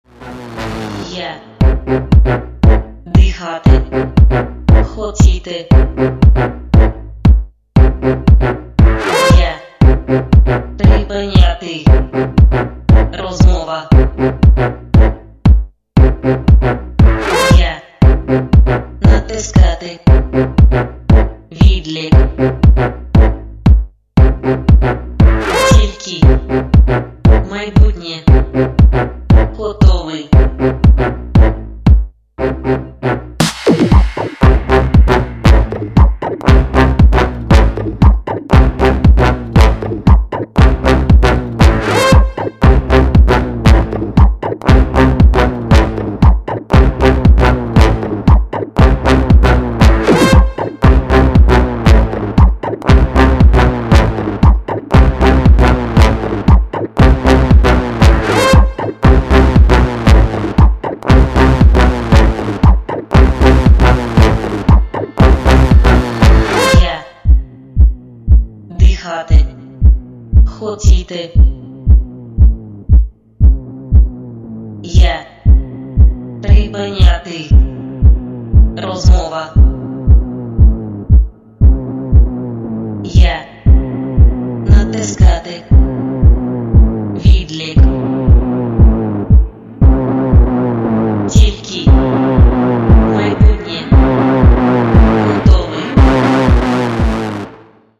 dance
Electronica
house